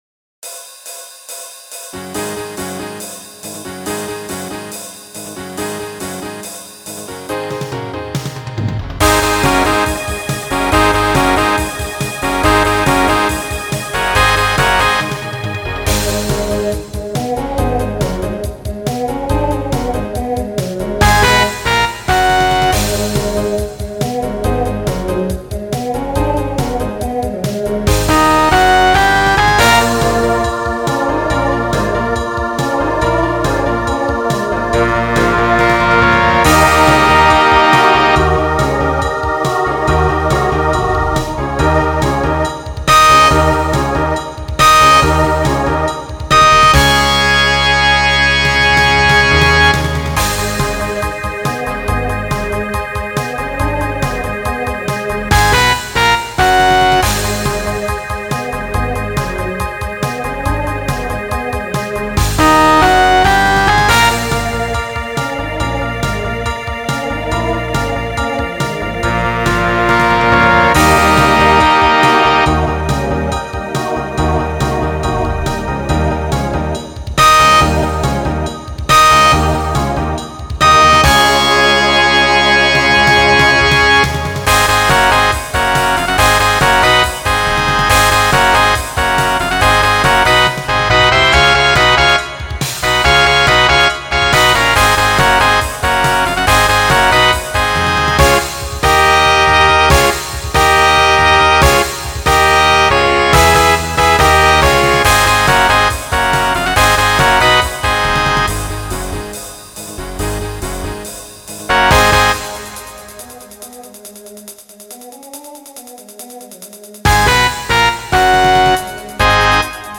Some SATB at the end
Voicing TTB Instrumental combo Genre Rock